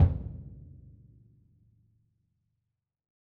BDrumNew_hit_v5_rr1_Sum.mp3